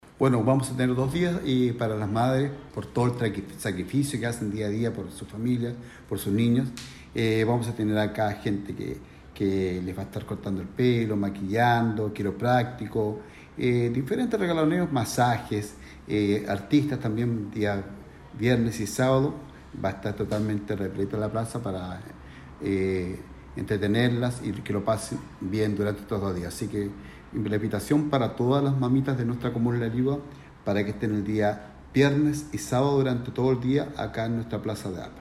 El alcalde de la comuna de La Ligua, Patricio Pallares Valenzuela, comentó parte del itinerario de actividades e invitó a participar en la celebración.
CUNA-1-ALCALDE-PALLARES-POR-CELEBRACION-DIA-DE-LA-MADRE.mp3